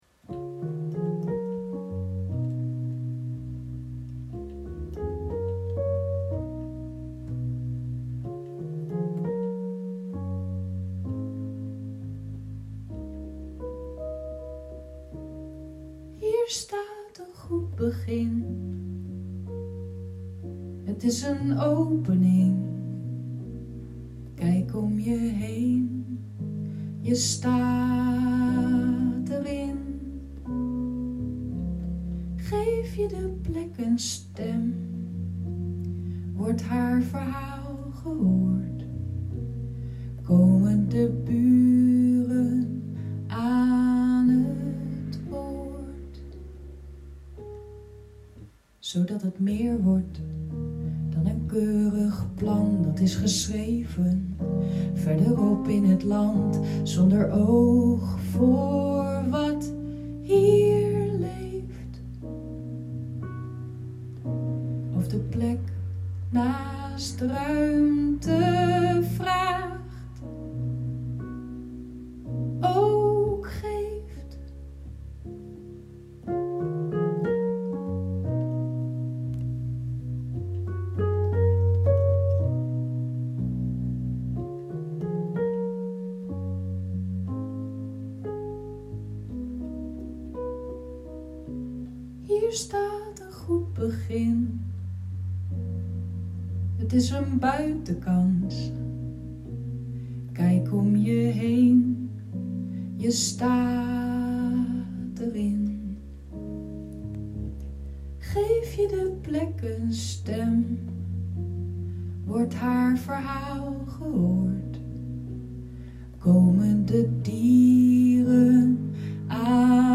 Vinkjes & vonkjes – het lied